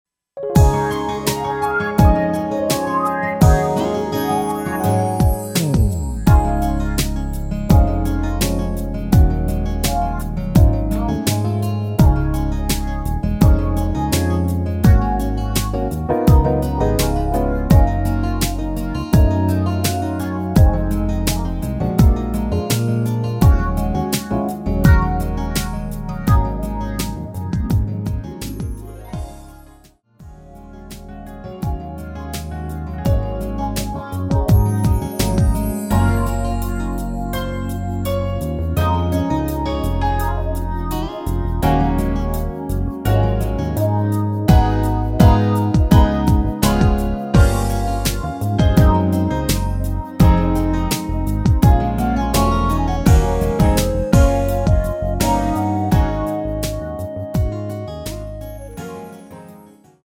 랩부분 삭제한 편곡 MR 입니다.
Db
◈ 곡명 옆 (-1)은 반음 내림, (+1)은 반음 올림 입니다.
앞부분30초, 뒷부분30초씩 편집해서 올려 드리고 있습니다.